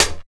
87 HAT.wav